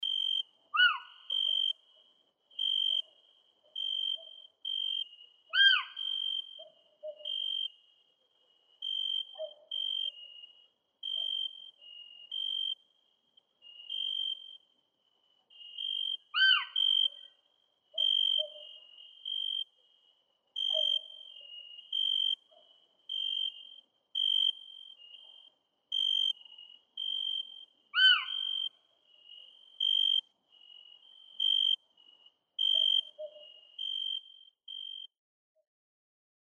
Звуки птиц
На этой странице собраны разнообразные звуки птиц: от щебетания воробьев до трелей соловья.